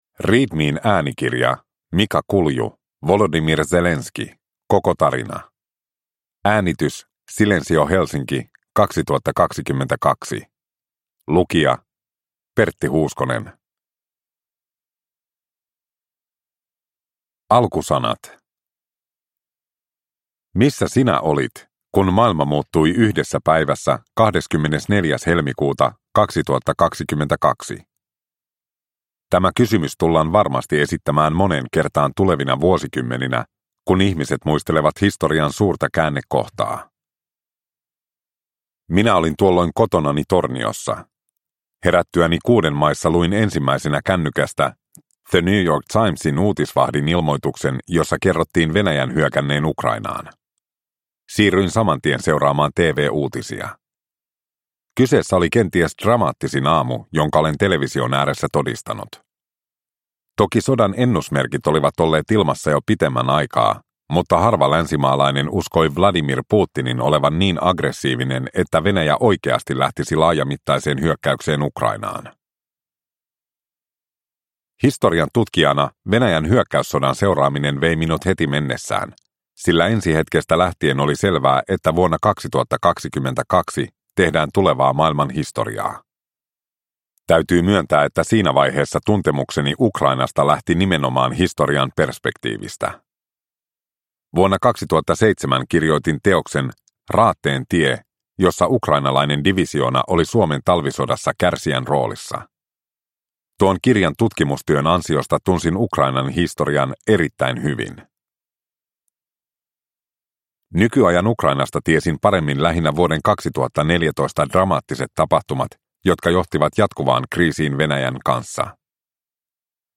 Zelenskyi - Koko tarina (ljudbok) av Mika Kulju